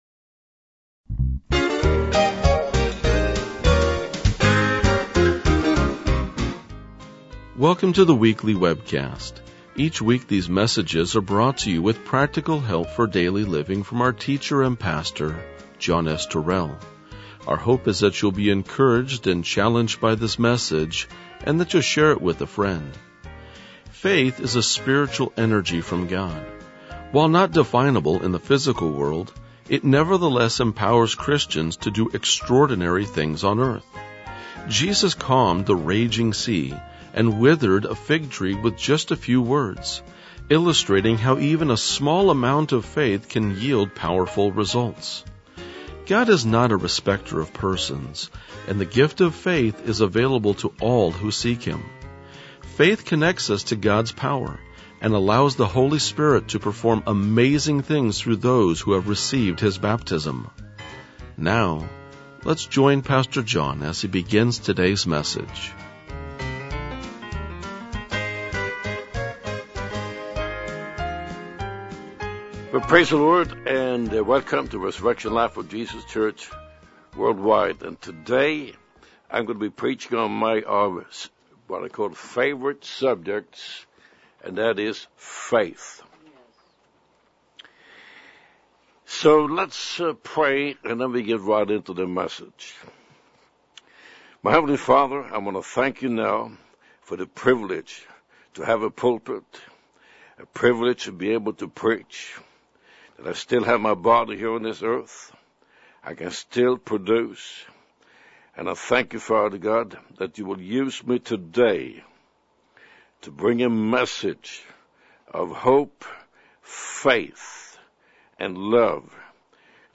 RLJ-2024-Sermon.mp3